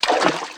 STEPS Water, Walk 03.wav